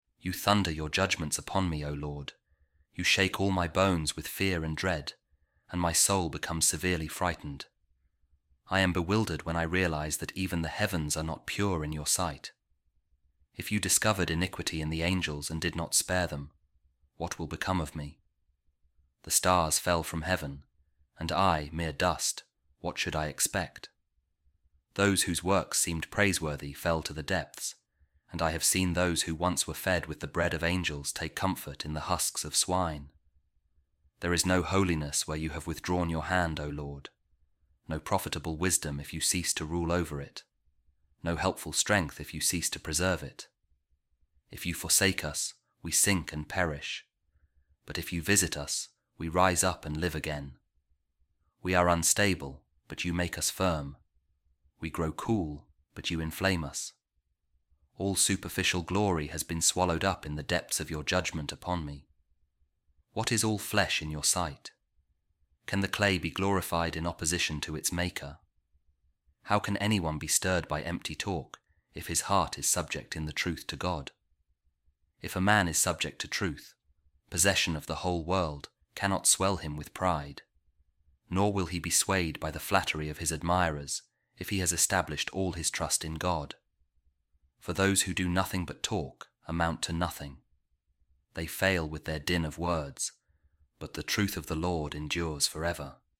A Reading From The Imitation Of Christ | The Truth Of The Lord Endures For Ever